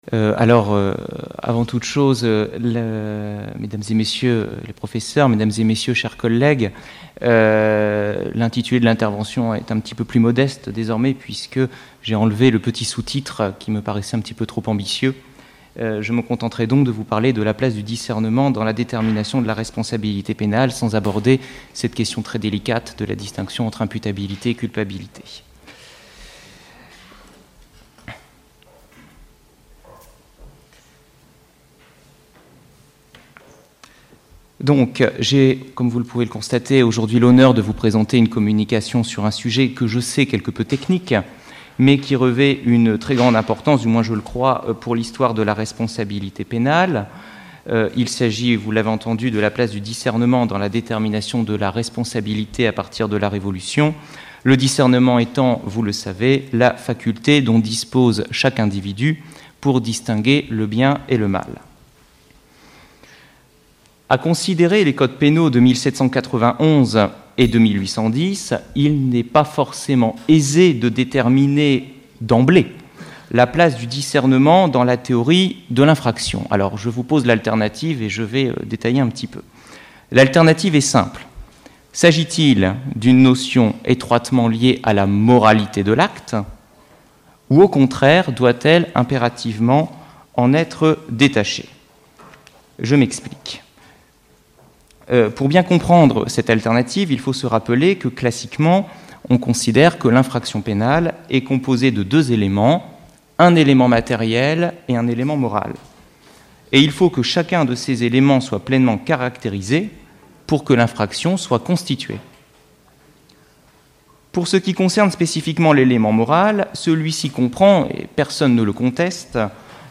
Du 1er au 4 juin dernier se sont déroulées à la Faculté de Droit de Tours, les Journées internationales de la Société d'Histoire du Droit, association scientifique internationale plus que centenaire. Le thème qui avait été proposé par notre Faculté était la Responsabilité.